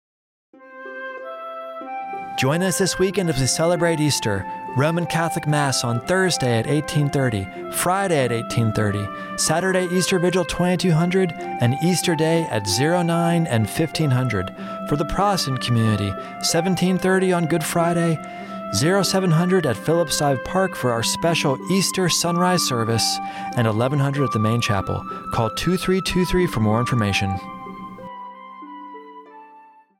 Radio Spot - Easter Services At Naval Station Guantanamo Bay